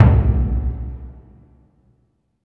Index of /90_sSampleCDs/Roland LCDP14 Africa VOL-2/PRC_Burundi Drms/PRC_Burundi Drms